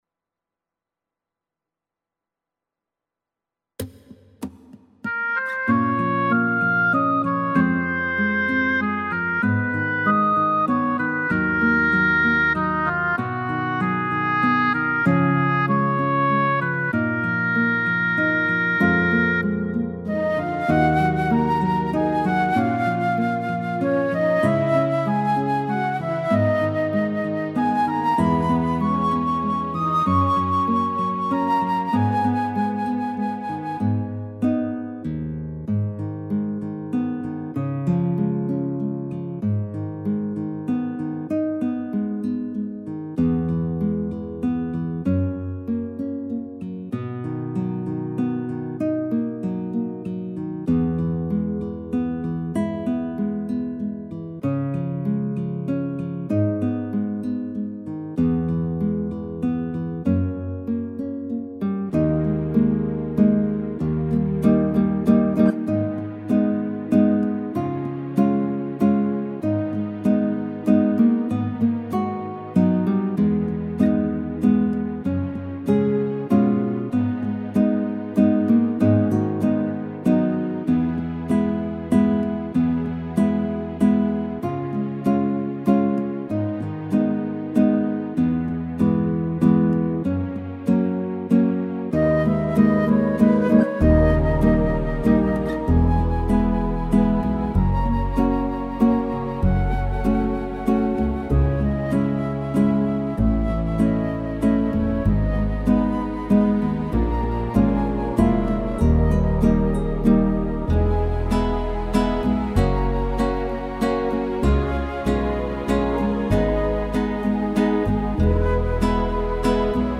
•   Beat  04.
(G#m) 4:20